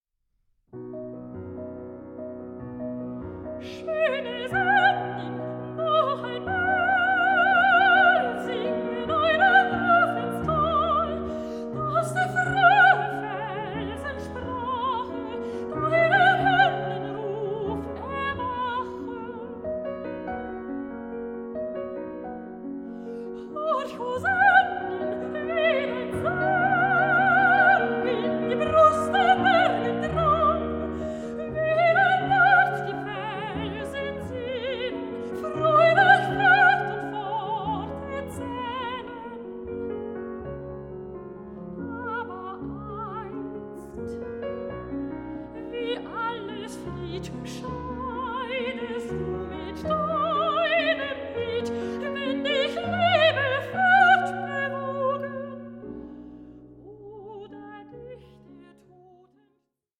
soprano
pianist